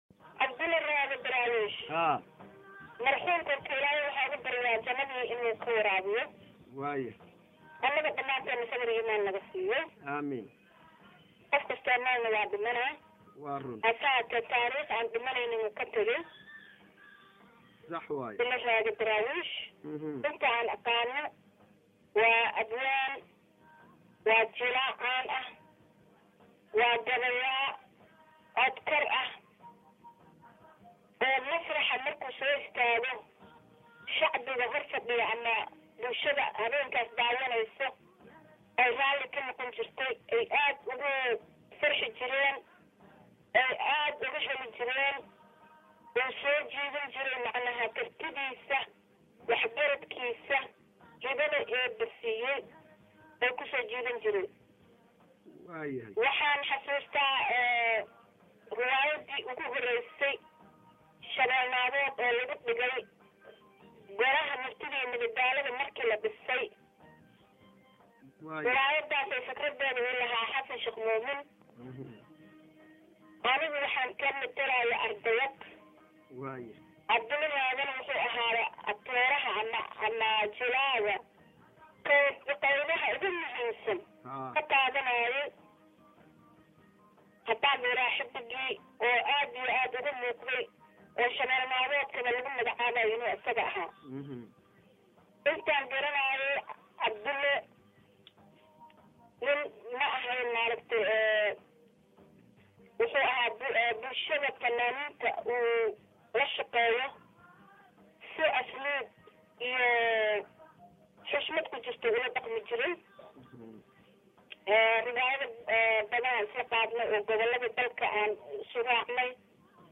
Bal ila dhageyso wareysi aan qadka telefoonka kaga qaaday muddo sii horreysay, wuxuuna u dhacay sidatan;-